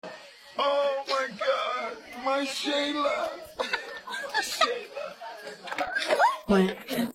Parte vocal